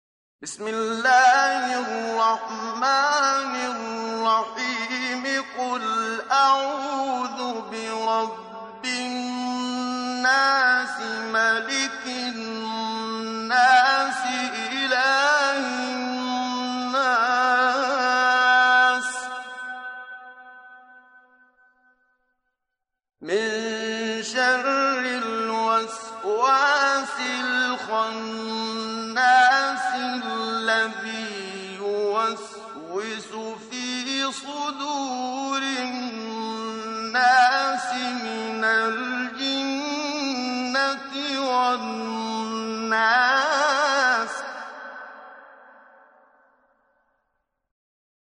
lecture mp3 Tajwîd (pour une bonne prononciation) (qdlfm) - 284 ko ;
(forme de récitation entre la vitesse normale du parler ["Hadr"] et la vitesse lente pour lire et réfléchir ["tartîl"] qui permet de connaître les règles de récitation coranique, l'une des trois branches de la Science de la récitation coranique ["‘ilm al-qirâ‘a"])
114-Surat_An_Naas_(Les_hommes)_Tajwid.mp3